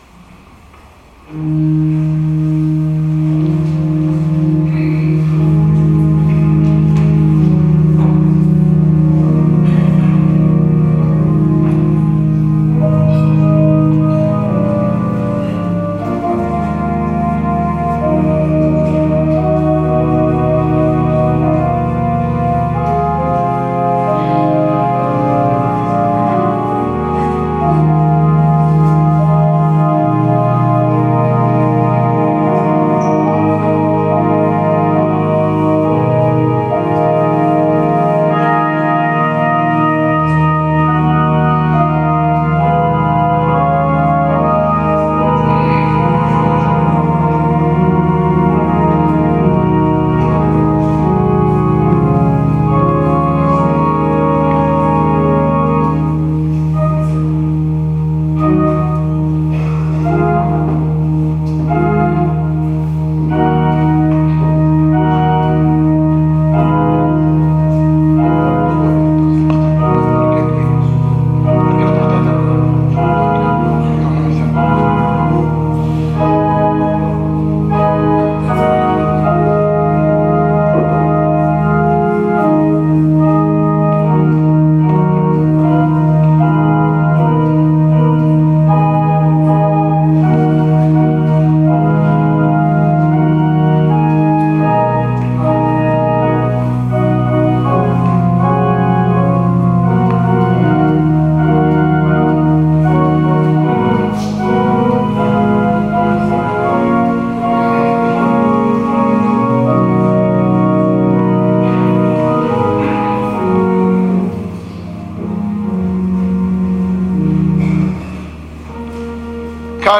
14.04.2017 – RITI DE “SU SCRAVAMENTU” NEL VENERDÌ SANTO
Omelie